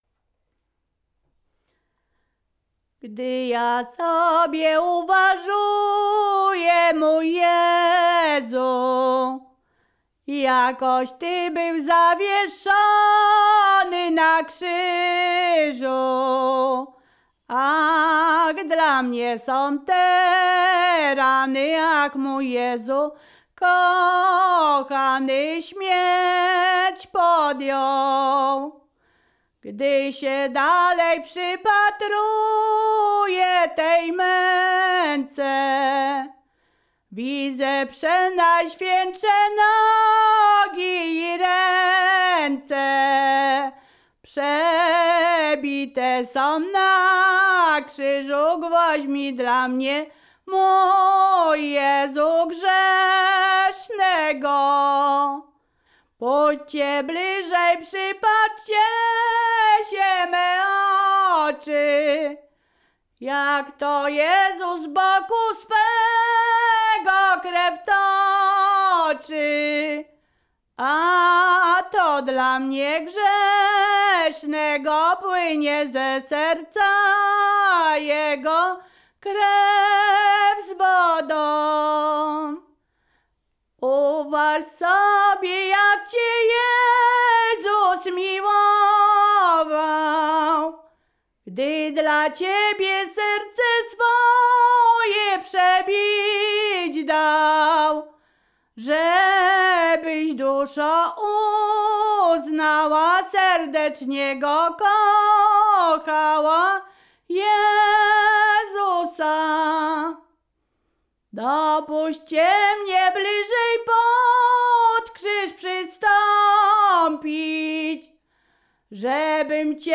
Wielkopostna
nabożne katolickie wielkopostne